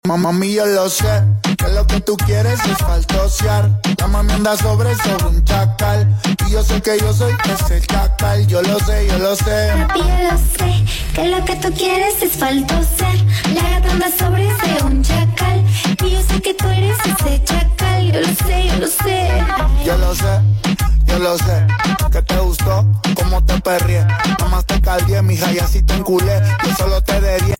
Kiss Sound